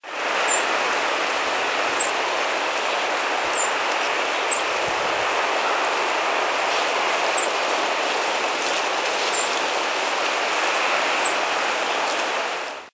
Fox Sparrow diurnal flight calls
Diurnal calling sequences:
Several birds calling from forest groundcover at dawn.